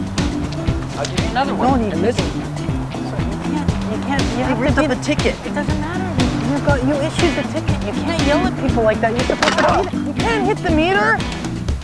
Grant shouts exasperatedly while looking at the violated meter, "You can't hit the meter!"